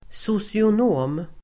Ladda ner uttalet
Uttal: [sosion'å:m]
socionom.mp3